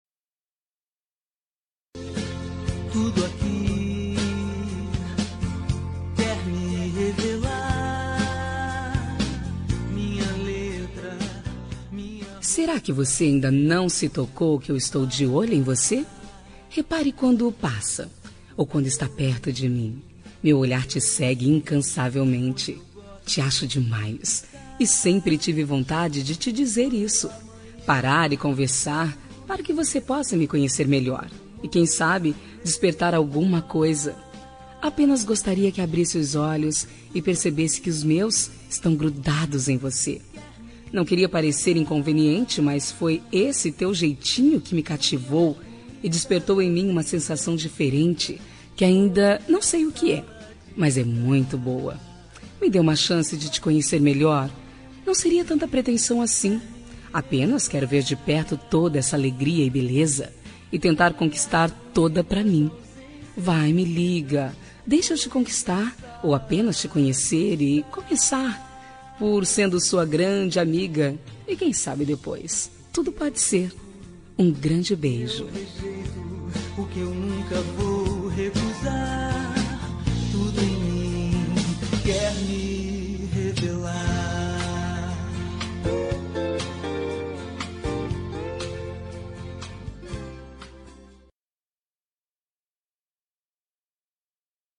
Telemensagem de Paquera – Voz Feminina – Cód: 2146 – Ousada